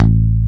Index of /90_sSampleCDs/Roland - Rhythm Section/BS _E.Bass 2/BS _Rock Bass
BS  ROCKBS0B.wav